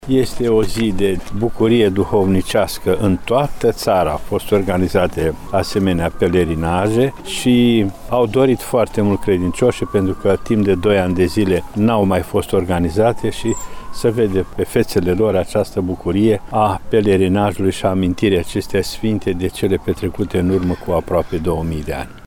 Sute de credincioşi au participat la Timişoara, la primul pelerinaj al Floriilor, organizat după izbucnirea pandemiei.